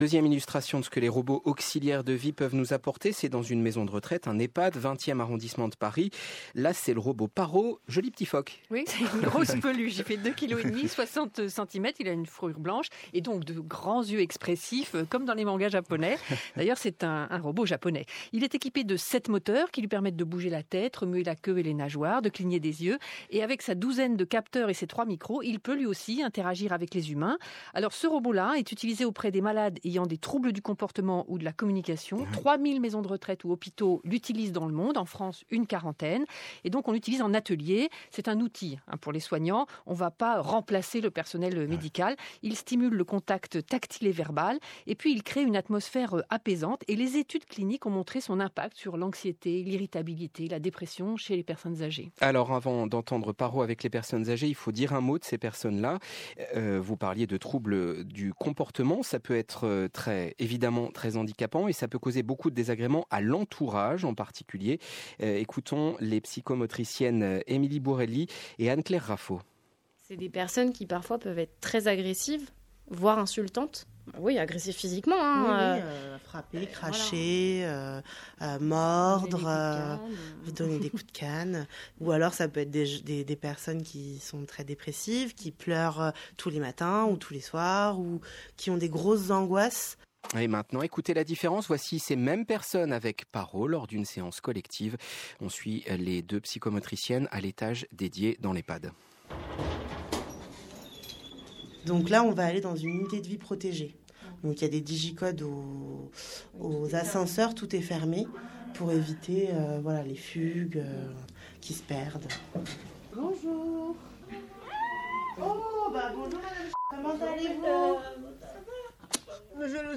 Reportage « Un jour en France » (Robots, nos nouveaux compagnons? / France Inter) sur PARO dans l’EHPAD Alquier Debrousse:
Reportage « Un jour en France » (Robots, nos nouveaux compagnons? / France Inter)